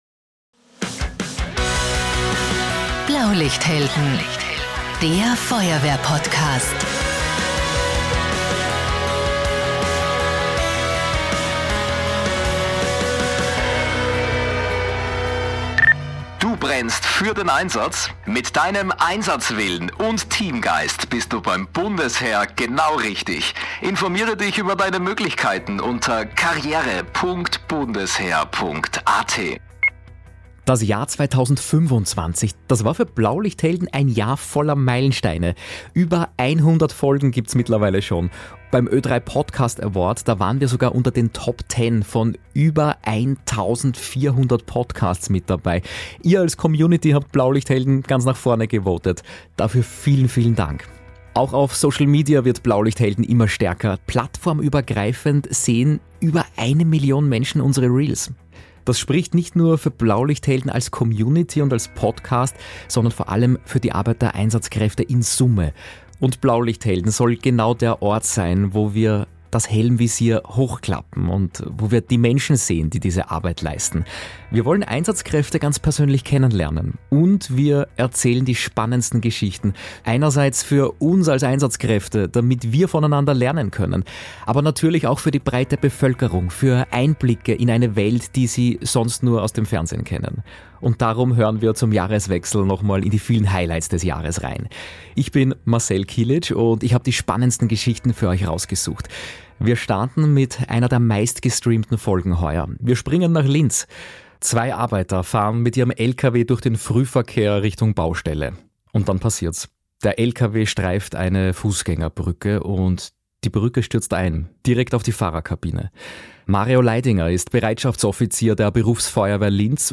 Im Feuerwehr-Podcast Blaulichthelden schildern Einsatzkräfte ihre Erlebnisse. Diese Folge ist ein Best-of jener Geschichten, die besonders in Erinnerung geblieben sind.